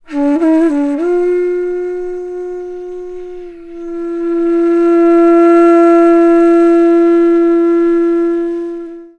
FLUTE-A02 -L.wav